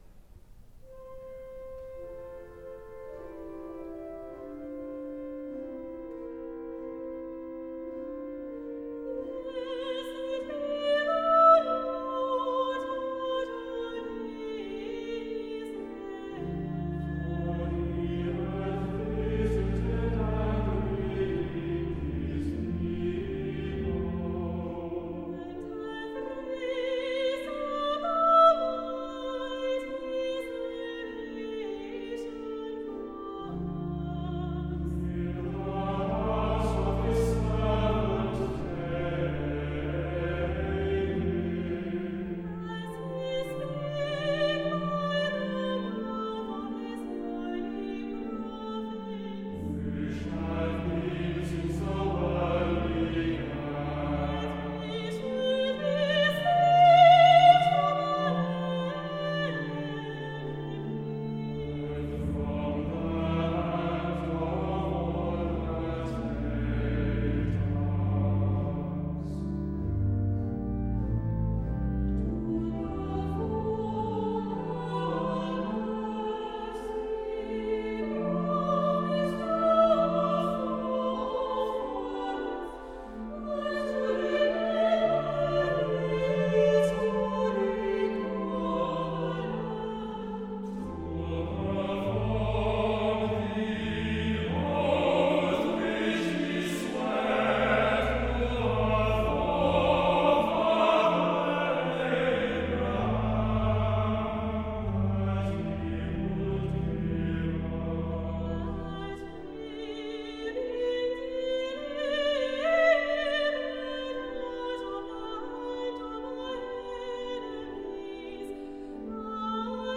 THE OXFORD CHOIR BENEDICTUS